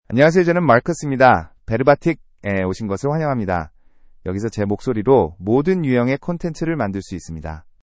Marcus — Male Korean (Korea) AI Voice | TTS, Voice Cloning & Video | Verbatik AI
MarcusMale Korean AI voice
Marcus is a male AI voice for Korean (Korea).
Voice sample
Listen to Marcus's male Korean voice.
Male